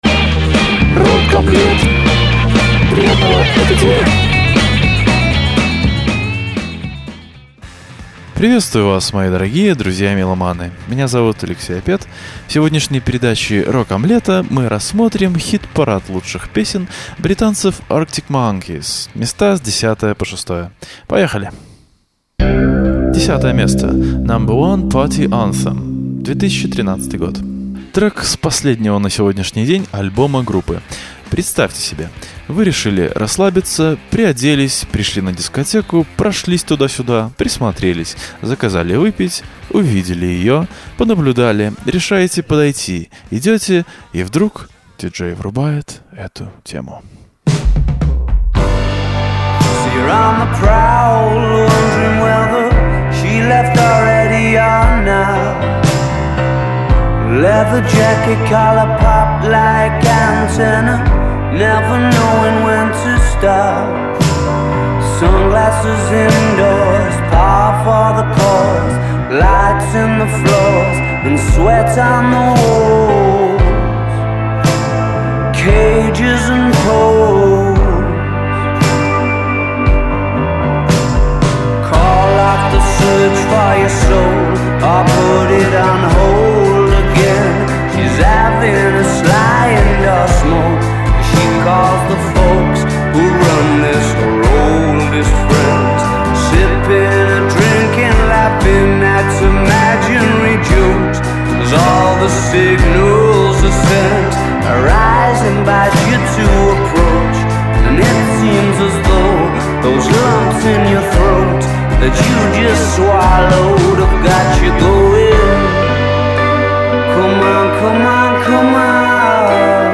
Трек с последнего на сегодняшний день альбома группы.